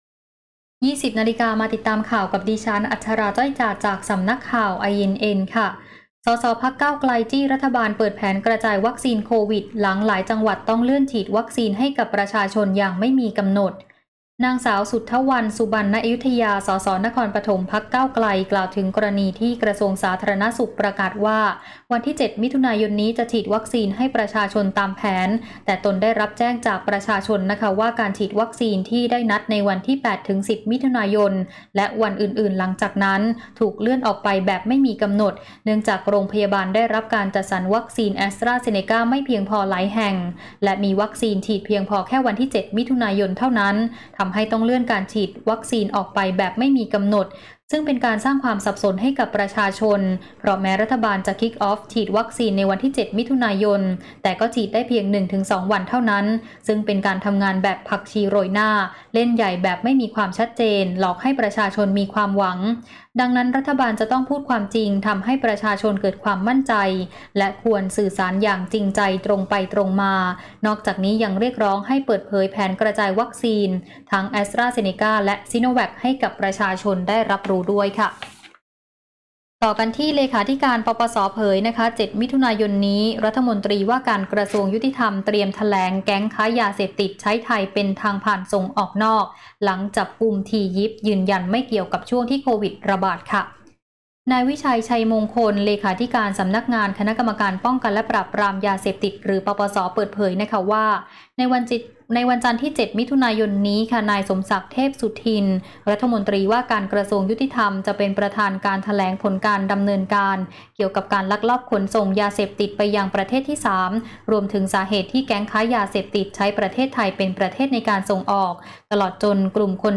ข่าวต้นชั่วโมง 20.00 น.